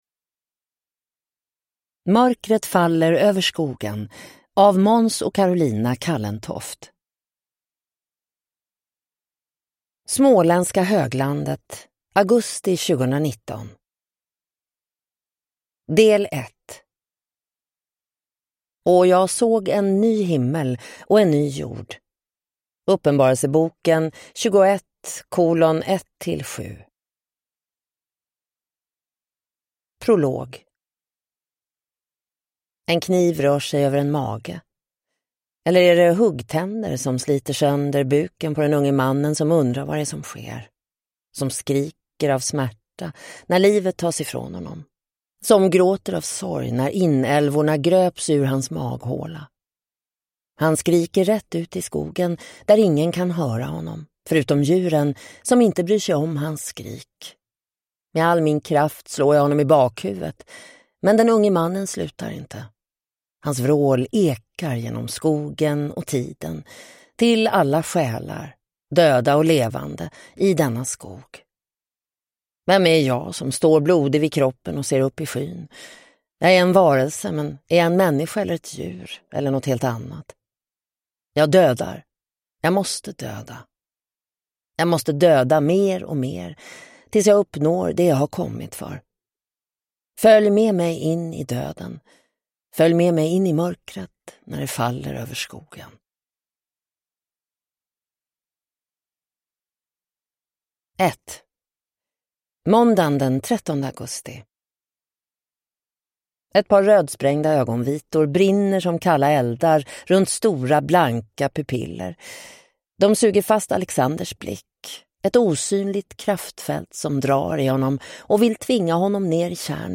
Mörkret faller över skogen – Ljudbok